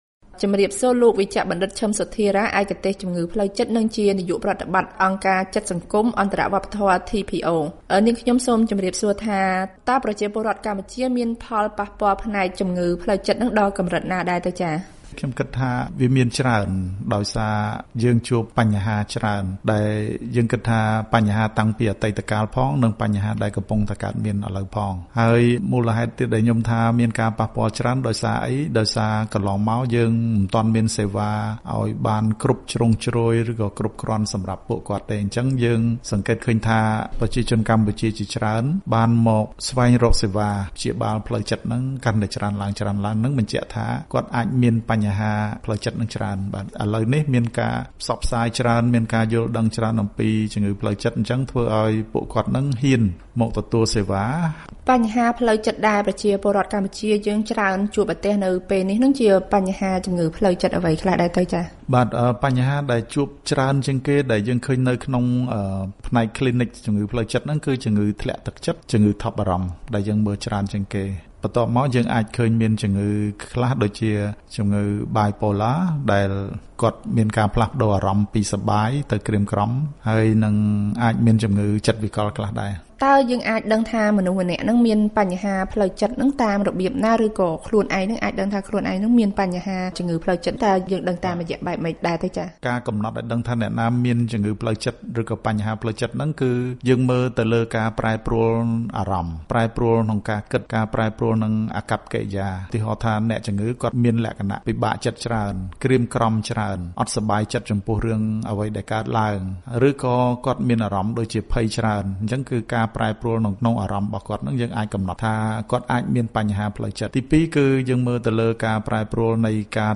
បទសម្ភាសន៍៖ ជំងឺផ្លូវចិត្តជាឃាតករលាក់មុខ បើអ្នកជំងឺនិងសាច់ញាត្តិមិនព្យាបាលជំងឺនេះ